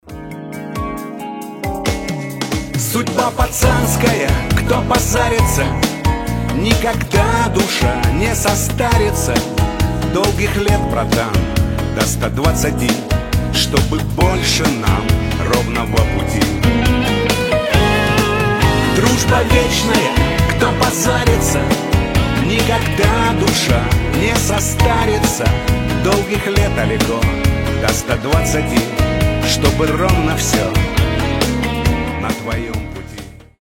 • Качество: 320, Stereo
душевные